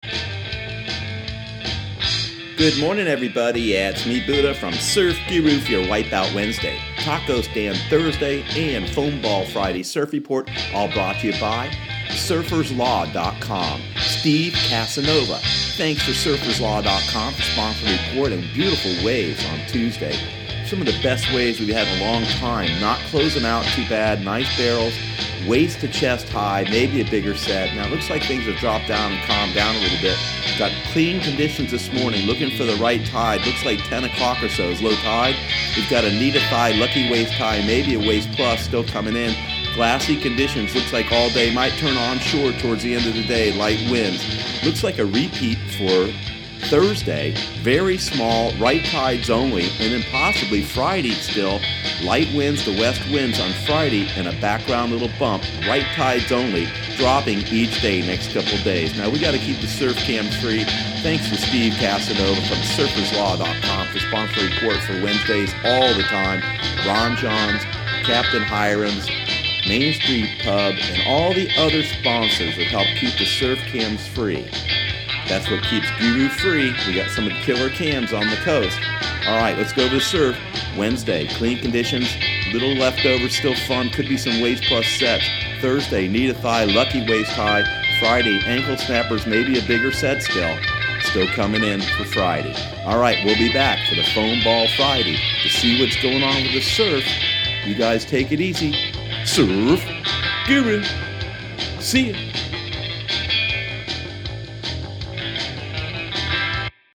Surf Guru Surf Report and Forecast 04/25/2018 Audio surf report and surf forecast on April 25 for Central Florida and the Southeast.